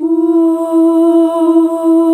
UUUUH   E.wav